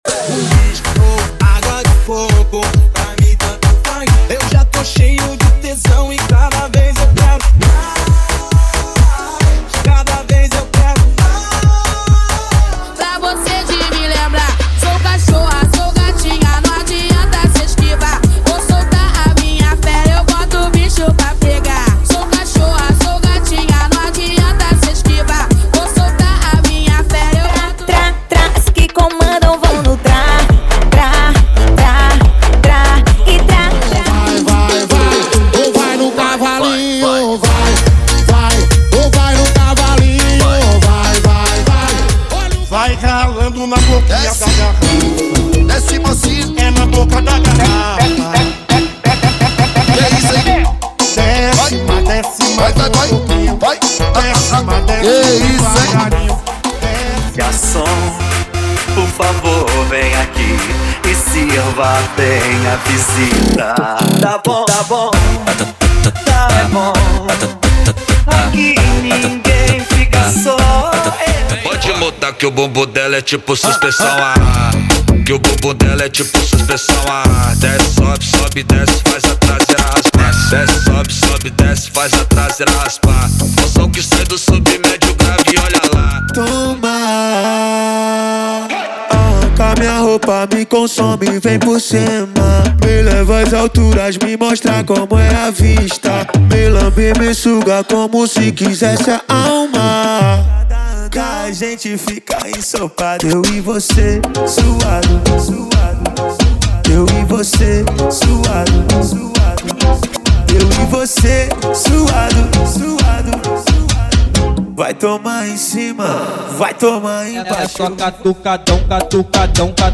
Funk Rave
Funk Tamborzão + Funk Rave
Funk Diversos
– Sem Vinhetas